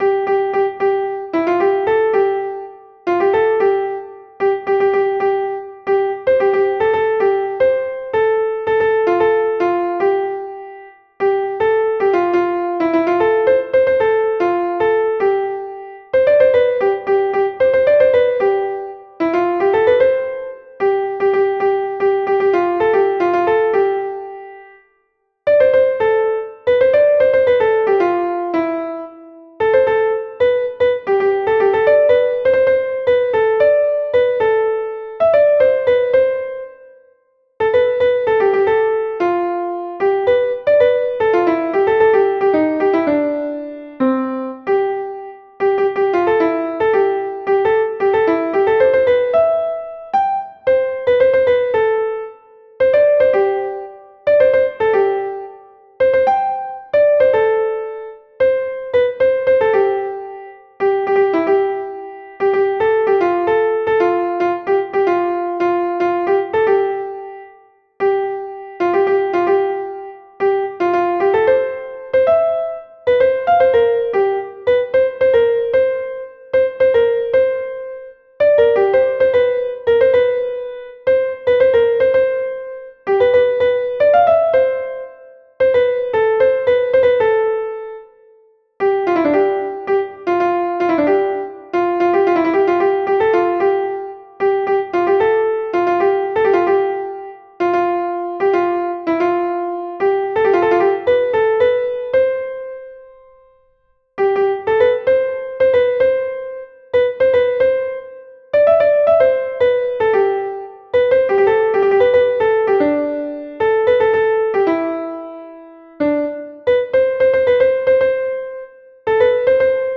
The piece is limited to 1/8th and 16th notes for simplicity.
Please follow the pdf and there is a piece of the music in Audio form to help you hear the motifs being visualised.